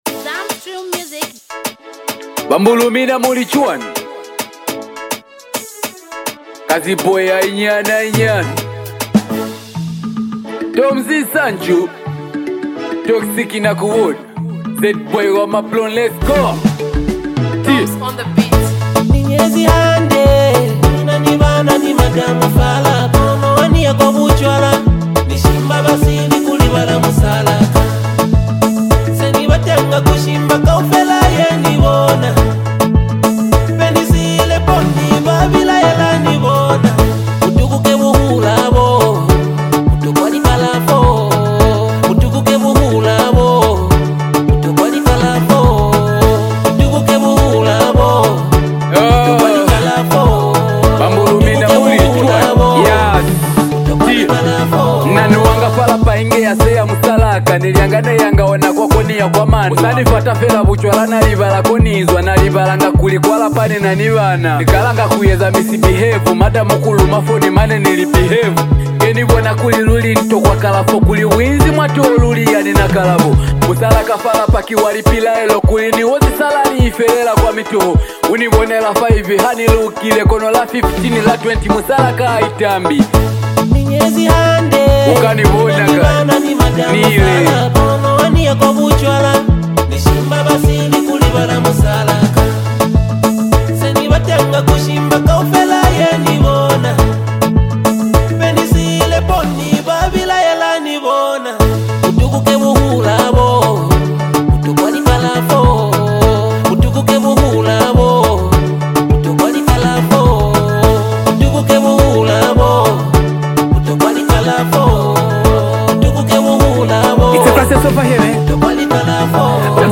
This EP blends emotion, culture, and smooth melodies